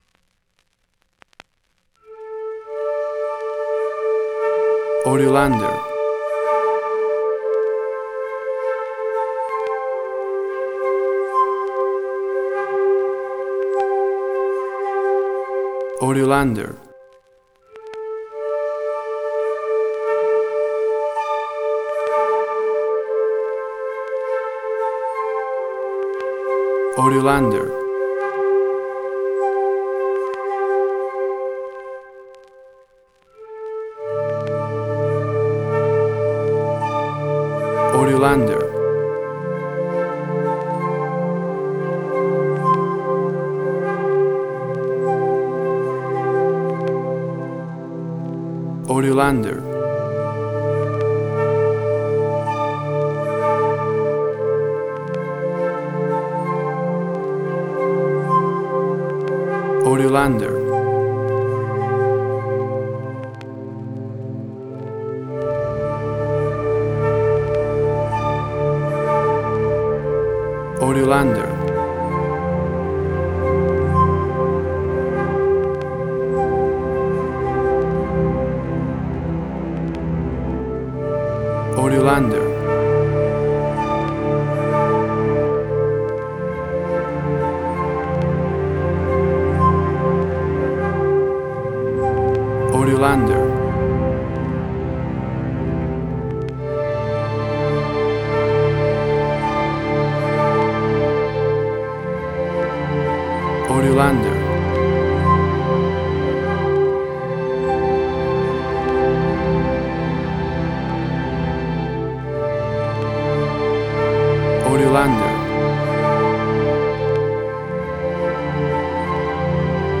Spaghetti Western
space, ethereal